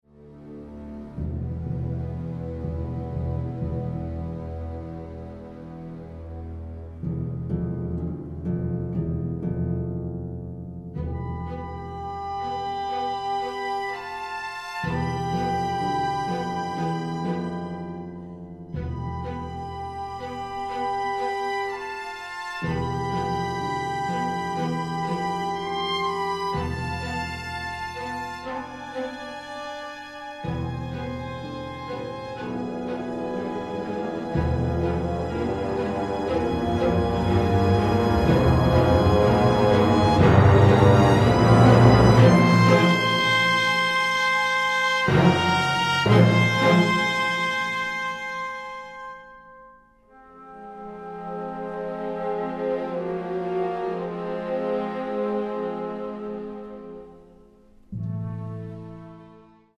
The score has been recorded in London